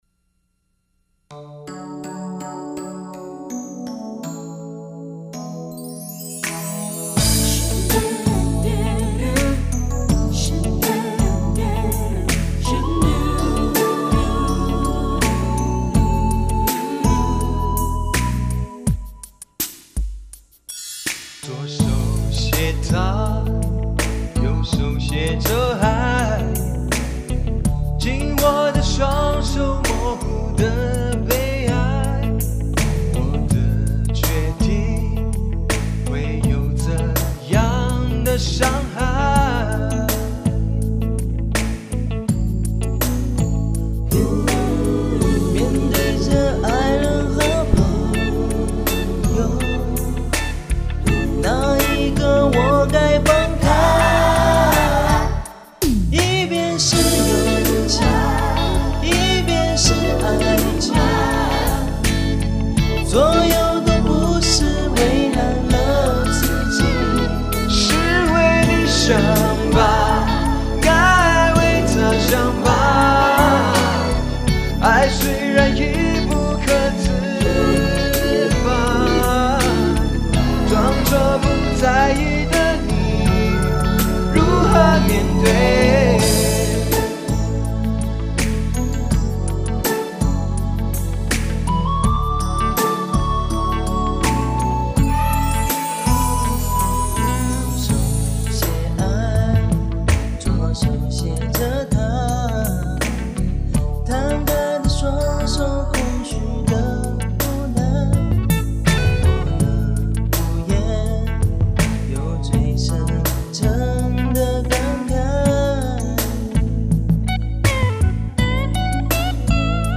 硬件:声籁 M6